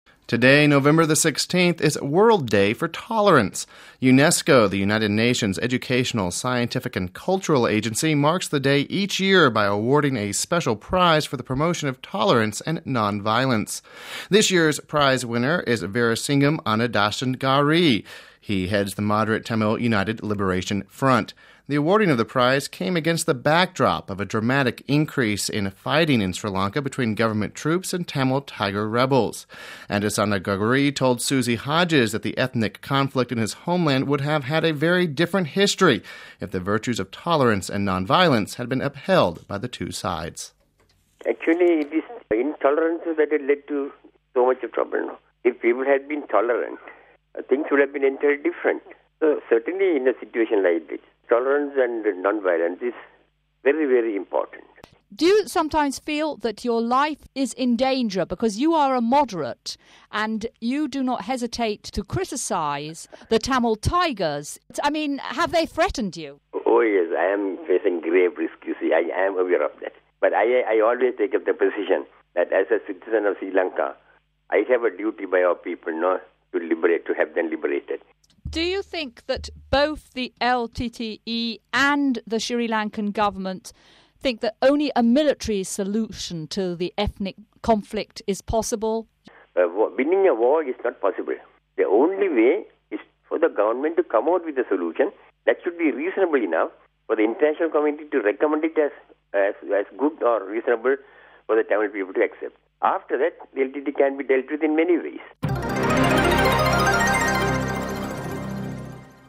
Home Archivio 2006-11-16 18:37:27 UNESCO Awards Prize to Sri Lankan (16 Nov 06 - RV) A Tamil moderate working for peace in Sri Lanka has won an award from the United Nations culture organization. He spoke to Vatican Radio...